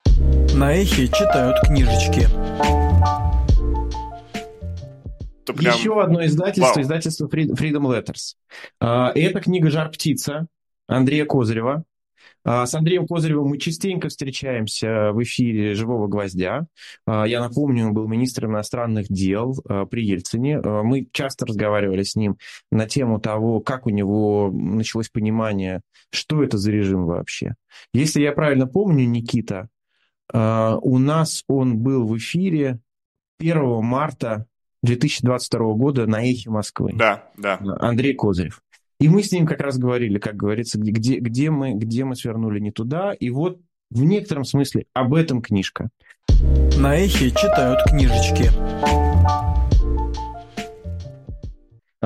Фрагмент эфира «Книжное казино» от 4 ноября